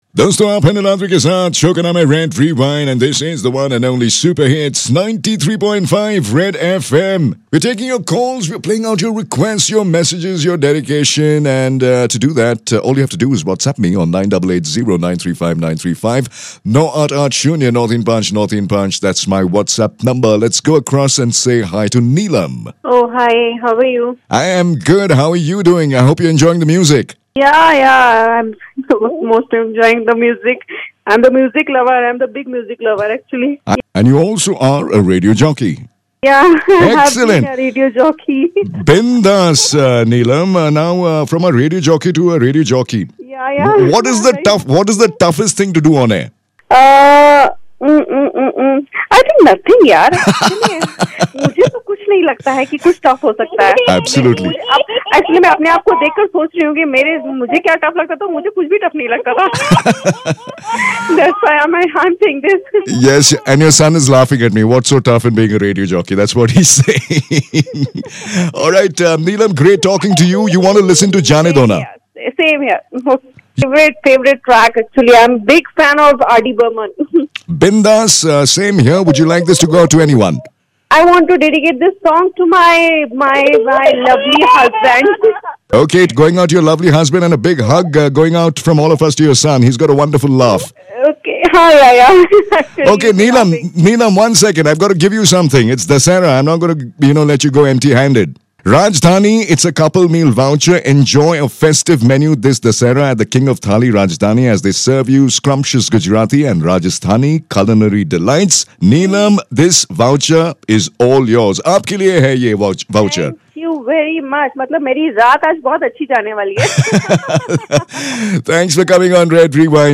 What happens when a 'Radio jockey' calls into another Radio jockey's show?